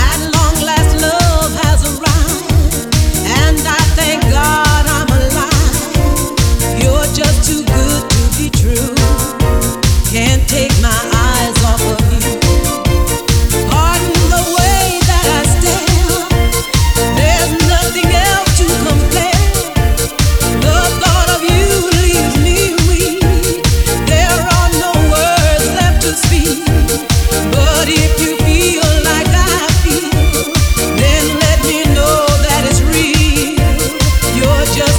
Fitness Workout